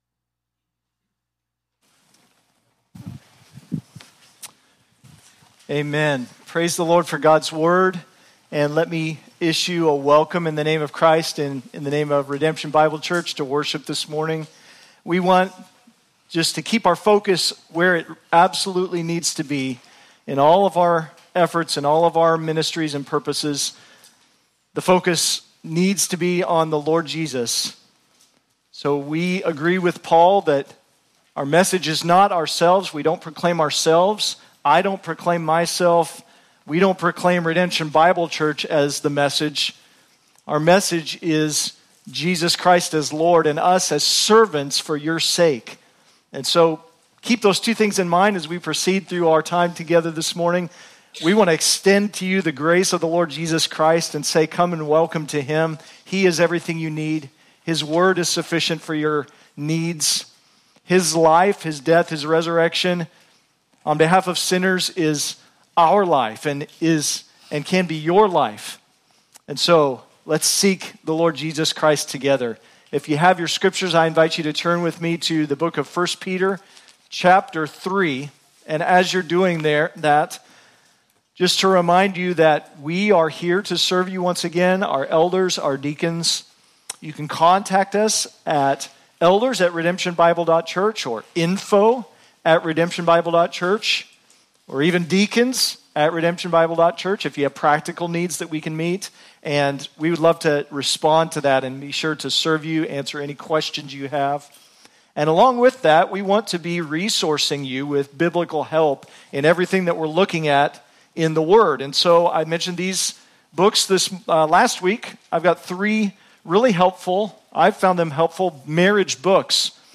Current Sermon